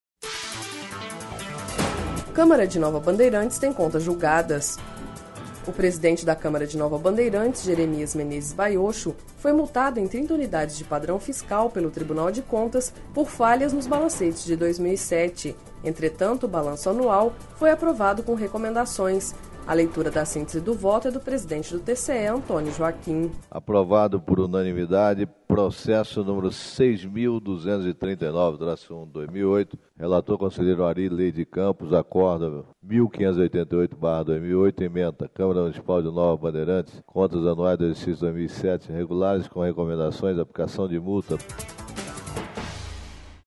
Entretanto, o balanço anual foi aprovado com recomendações./ A leitura da síntese do voto é do presidente do TCE, Antonio Joaquim.// Sonora: Antônio Joaquim – conselheiro presidente do TCE-MT